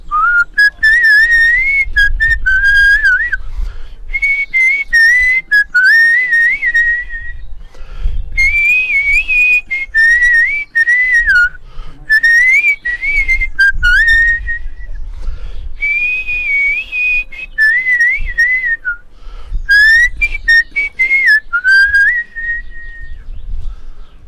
Sifflement après le dariolage
sifflement
Pièce musicale inédite